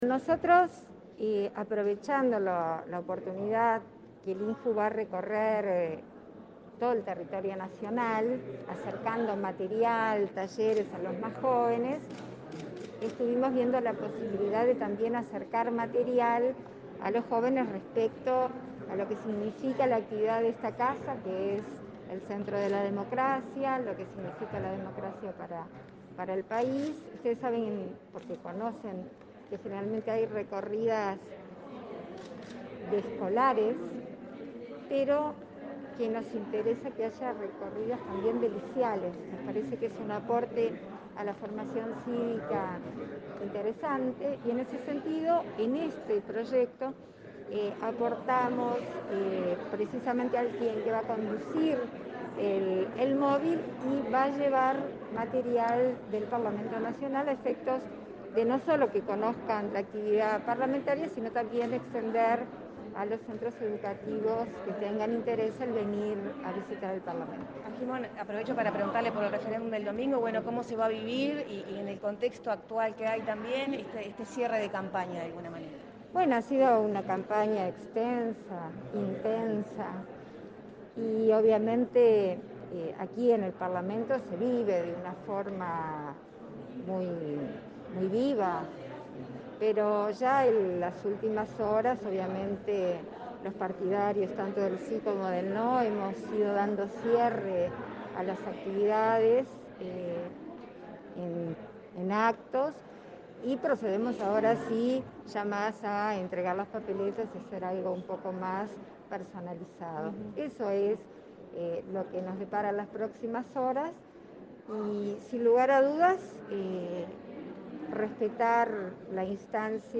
Declaraciones a la prensa de la vicepresidenta, Beatriz Argimón
La vicepresidenta de la República, Beatriz Argimón, dialogó con la prensa, luego de participar este jueves 24 de la presentación del INJU Móvil, un